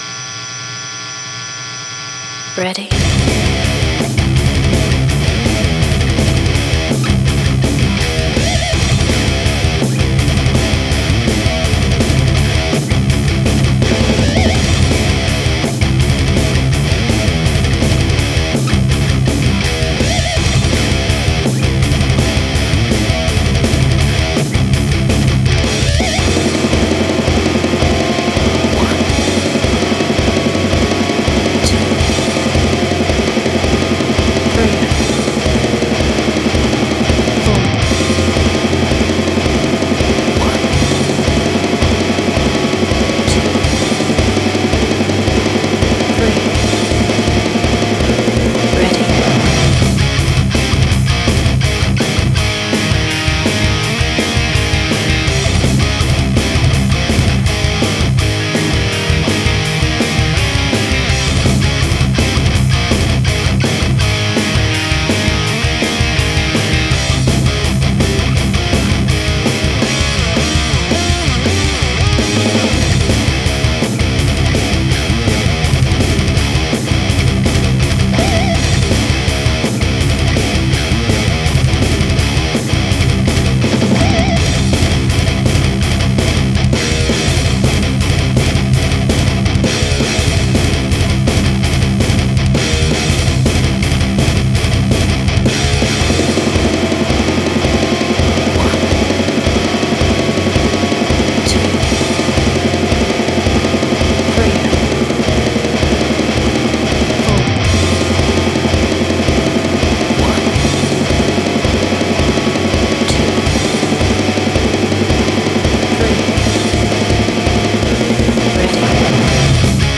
準備万端 用意周到といったイメージ。